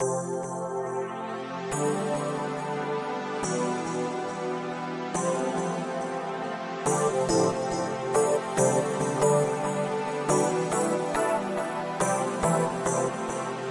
描述：舞蹈音乐模式部分迪斯科音乐舞蹈背景恍惚声音跳舞音乐混合迪斯科跳舞